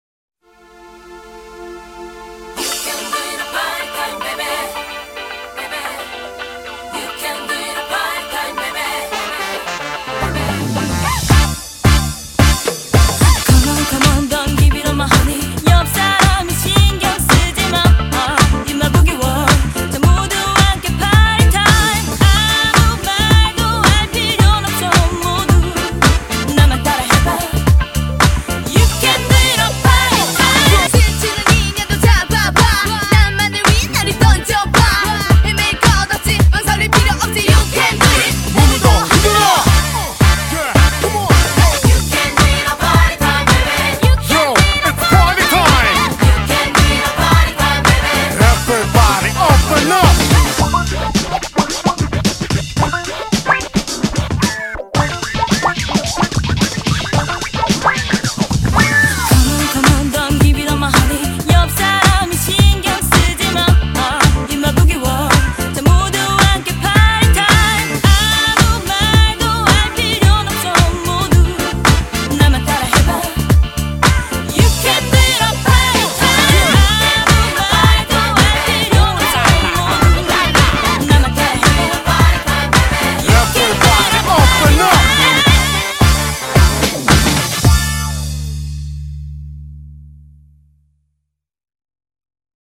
BPM110--1
Audio QualityPerfect (High Quality)